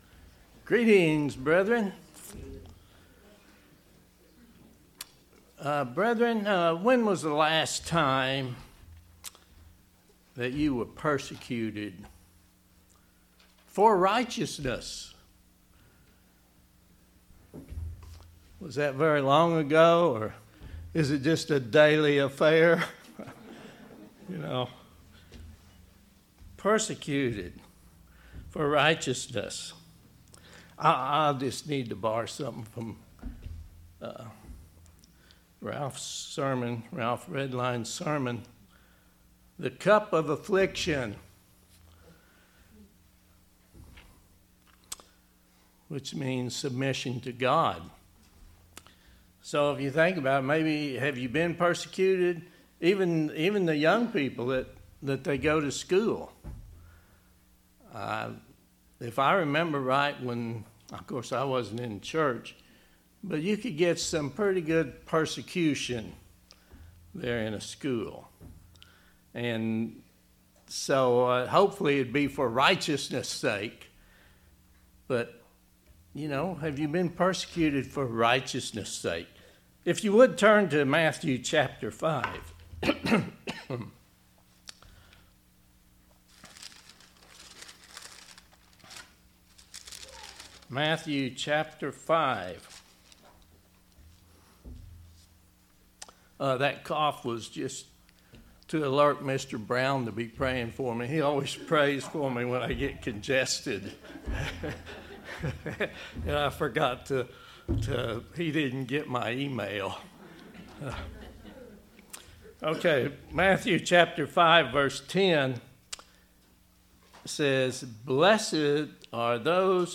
In this sermon, we examine why God allows persecution.